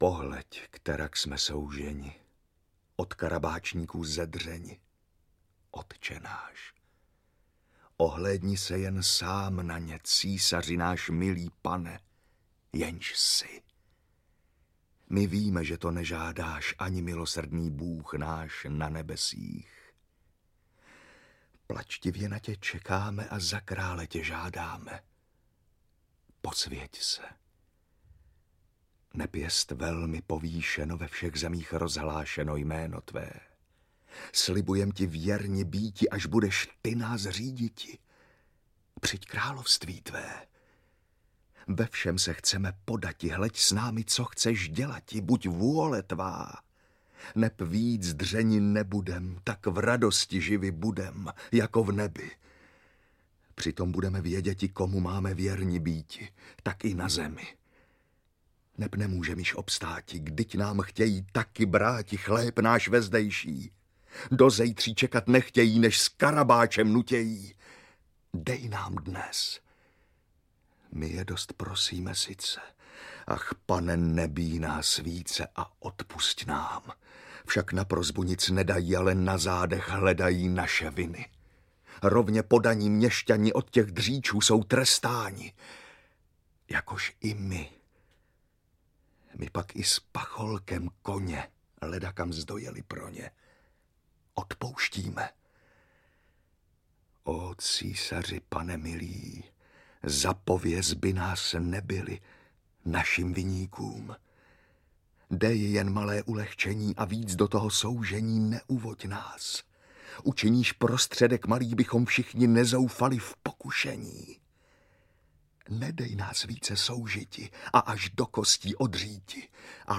Čtveročasí básnictví českého 2 audiokniha
Ukázka z knihy